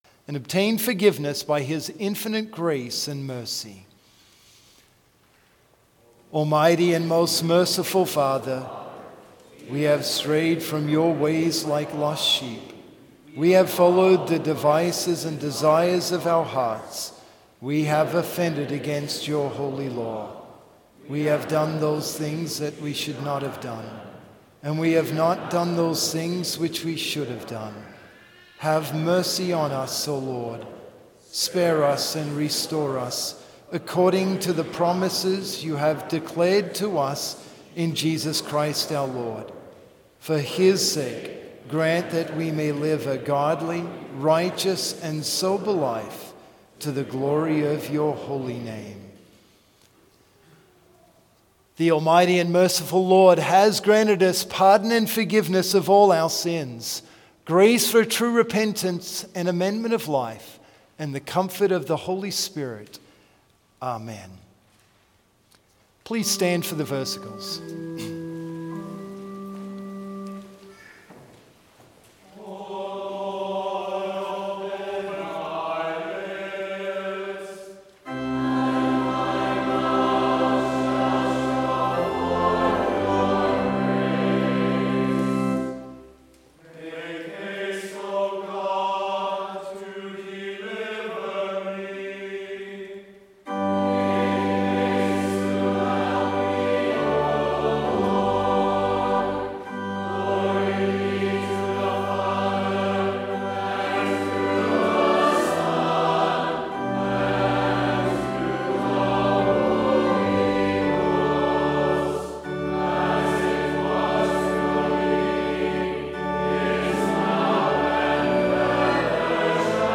Complete service audio for Lent - March 22, 2023